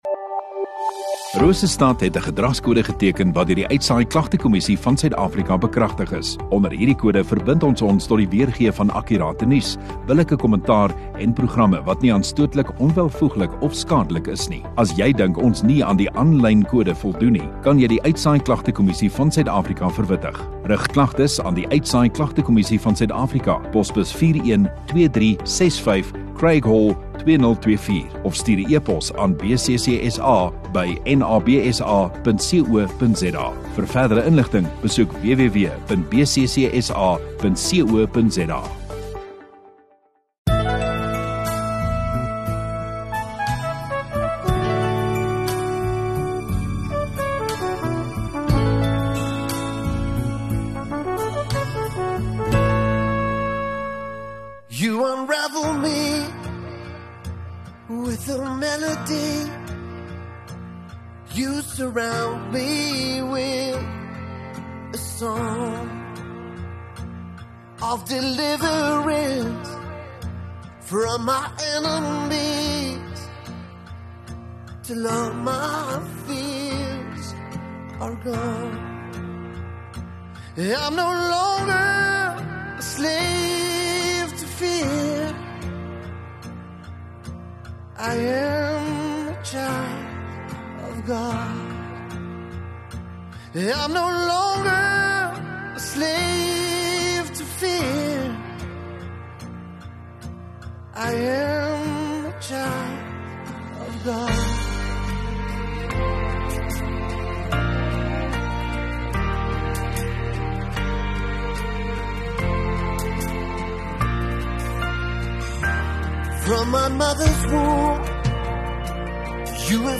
31 Aug Sondagoggend Erediens